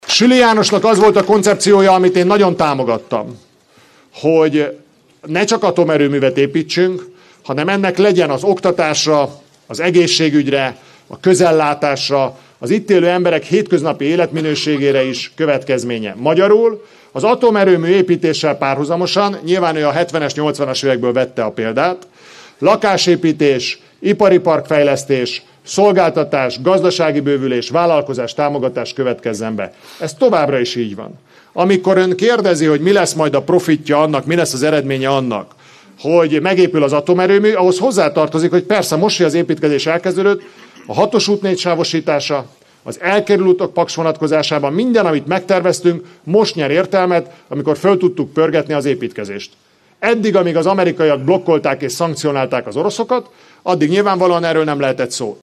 A Lázárinfó Expresszen a miniszter Süli Jánossal, a választókörzet országgyűlési képviselőjével várta az érdeklődőket a Csengey Dénes Kulturális Központ előtti téren. Lázár János többek mellett beszélt a Paksi Atomerőmű bővítéséhez kapcsolódó térségfejlesztési projektekről és az ehhez kapcsolódó tervekről is.